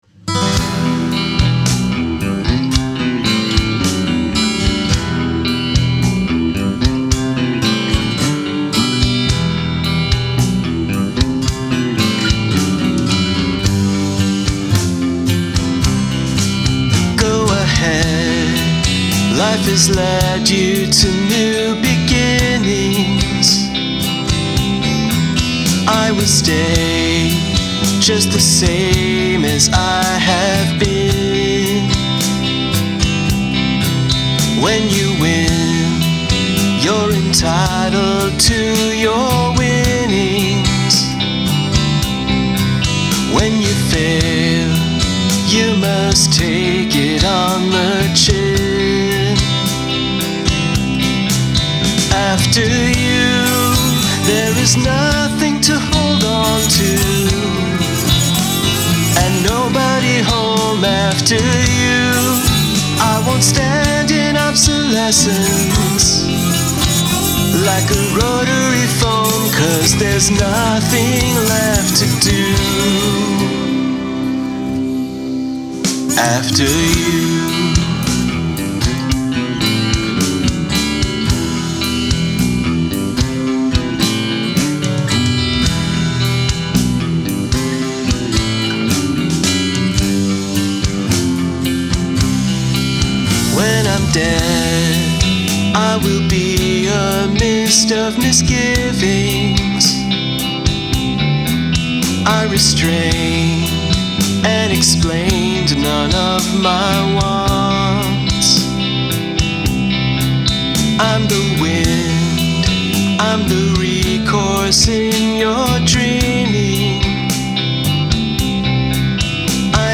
Counter Melody
The bass line is my favorite part.